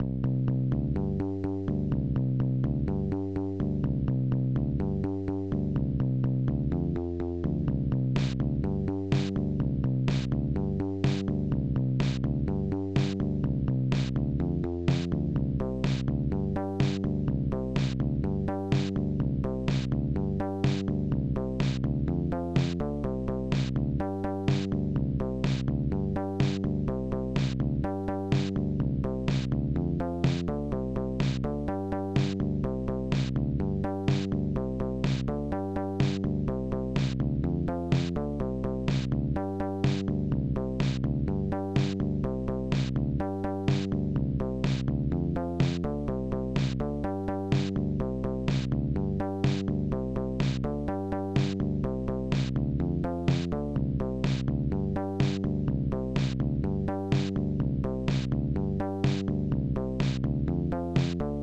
Protracker Module
Instruments elecbass